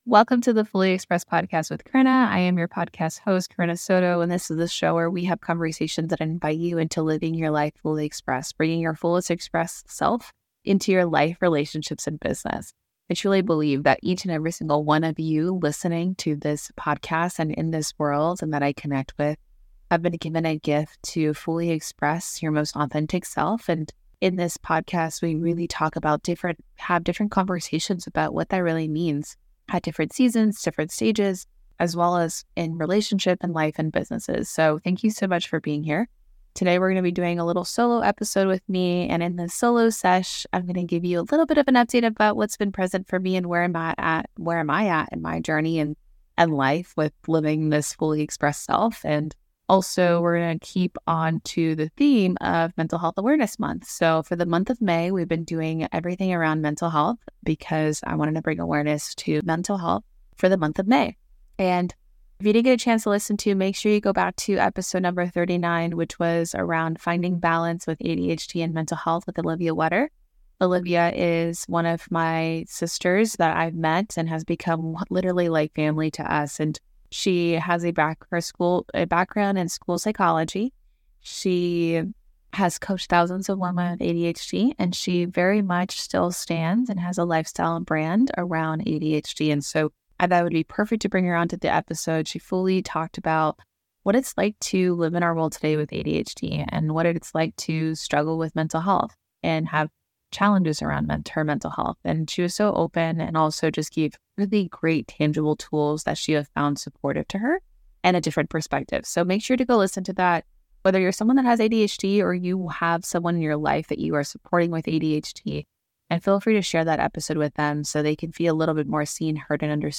Have you ever thought about the life-saving impact of simply checking in on someone's mental health? In this heartfelt solo episode of "The Fully Expressed," I dive deep into the importance of mental health awareness, particularly during Mental Health Awareness Month.